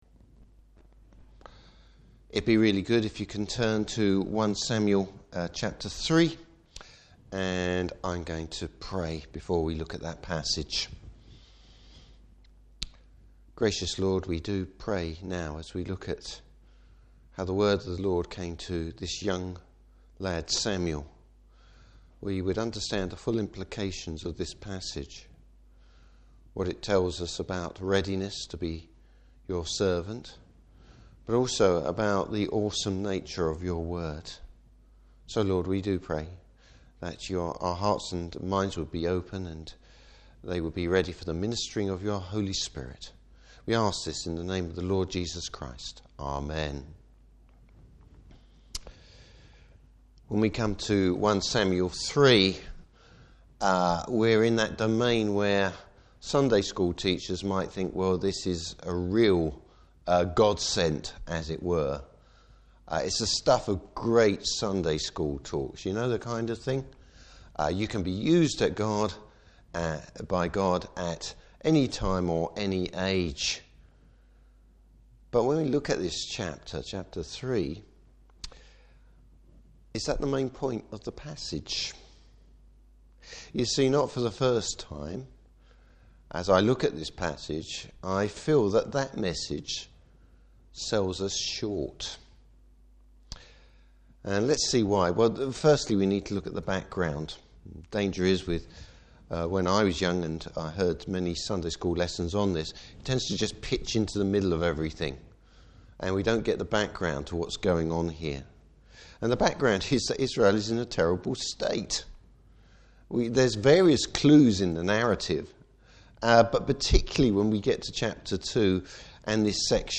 Service Type: Evening Service Samuel’s calling, and his uncomfortable first ‘Word’ from the Lord!